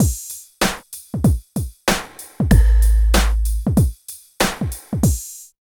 90 DRUM LP-R.wav